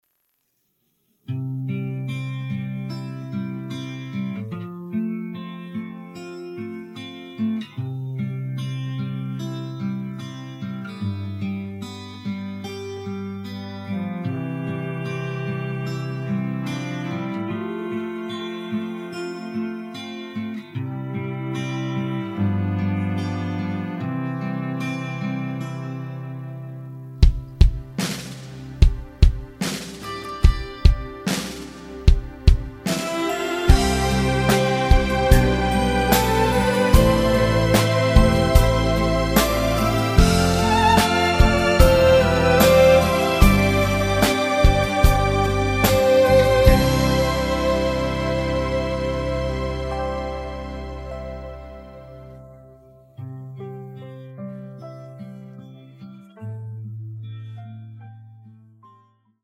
음정 원키
장르 가요 구분